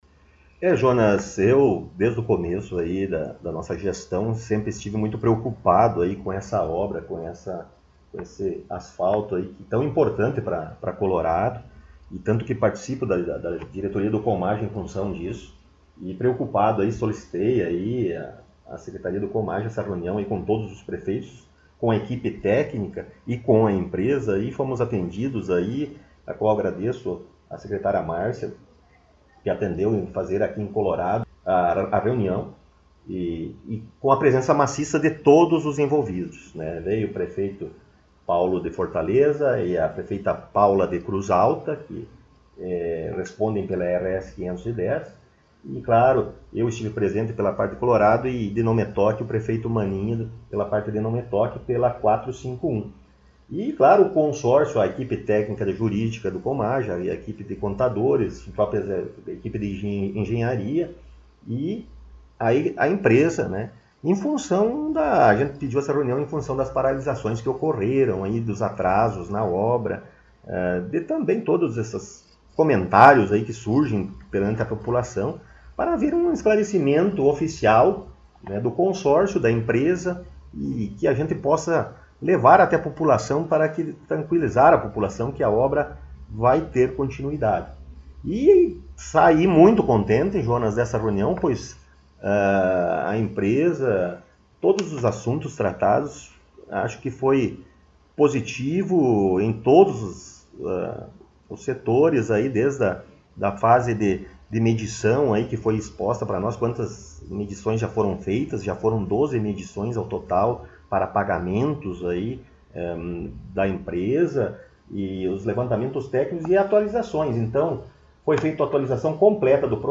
Estivemos mais uma vez em entrevista com o prefeito Rodrigo Sartori em seu gabinete.